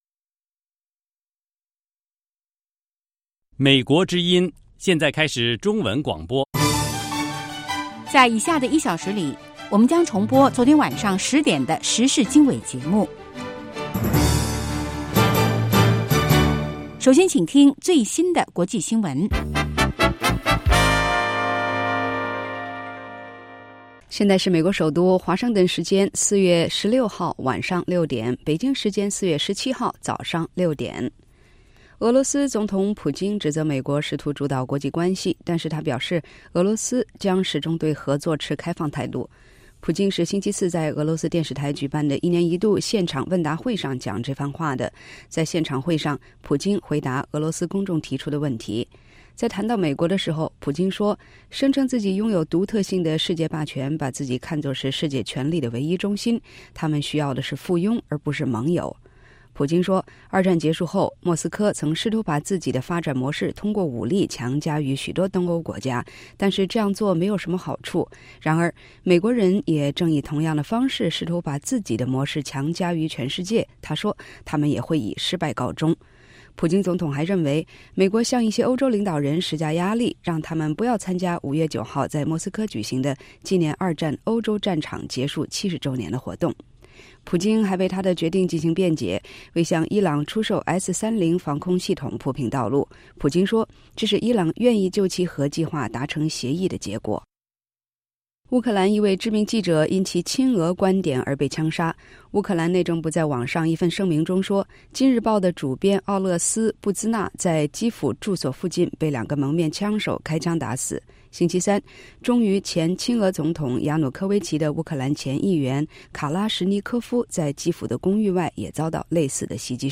北京时间早上6-7点广播节目 这个小时我们播报最新国际新闻，并重播前一天晚上10-11点的时事经纬节目。